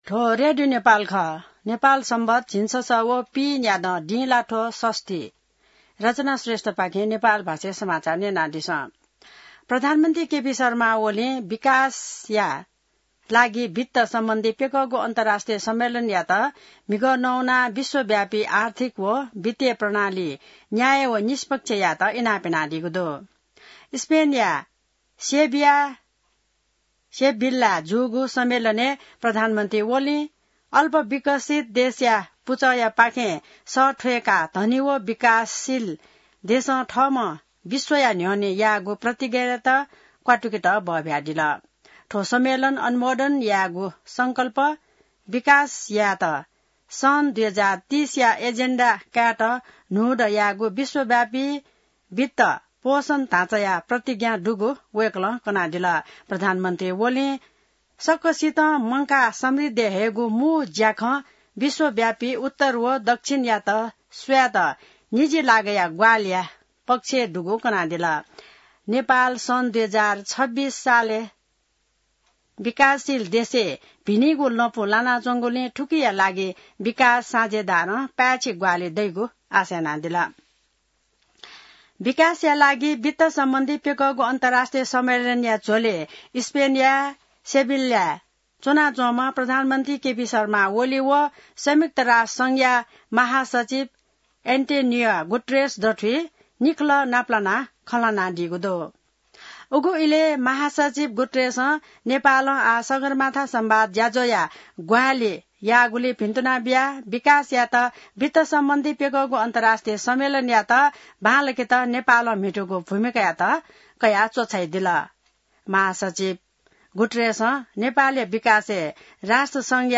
An online outlet of Nepal's national radio broadcaster
नेपाल भाषामा समाचार : १७ असार , २०८२